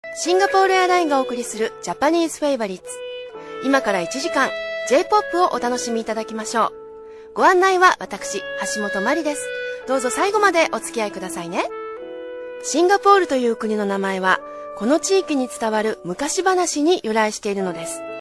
Sprecherin japanisch für TV / Rundfunk / Industrie.
Sprechprobe: Werbung (Muttersprache):
Professionell female voice over artist from Japan.